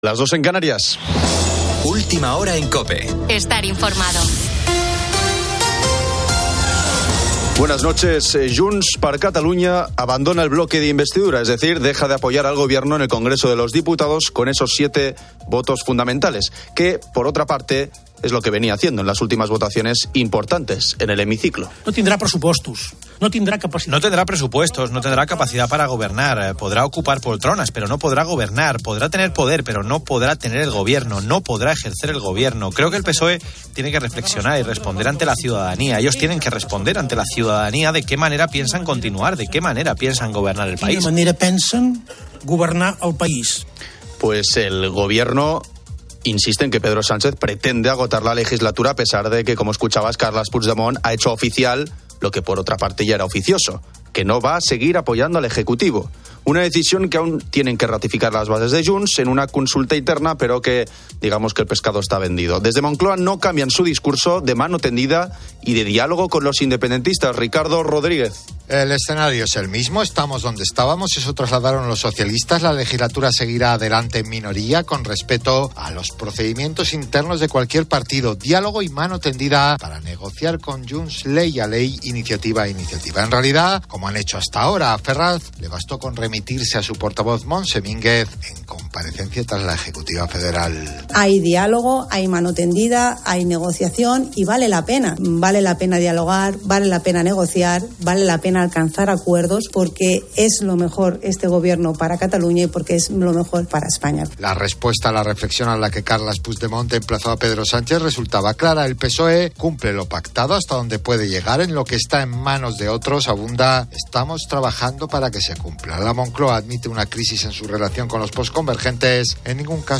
Carlos Moreno "El Pulpo" pone las calles y Ángel Expósito presenta la linterna